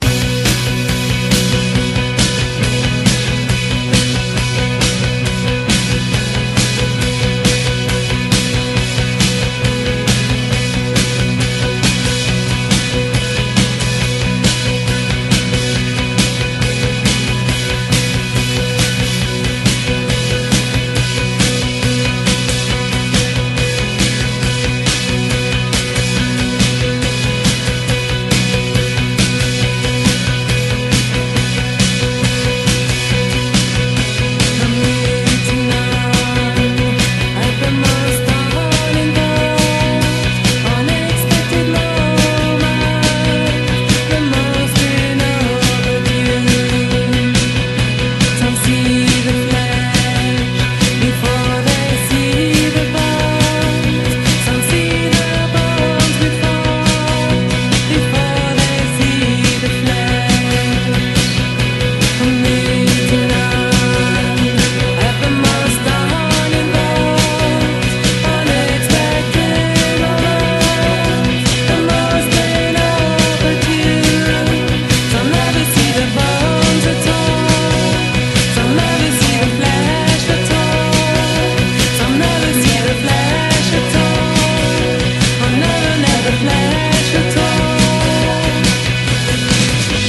INDIE POP / BREAKBEATS
ざっくりとしたアコギのフォーキー・グルーヴにハンド・クラップがハッピー度マックスな名曲
粒子的なシタール・フレーズとエスニックなエッセンスが絶妙にブレンドされた
シタールやタブラが絡み合う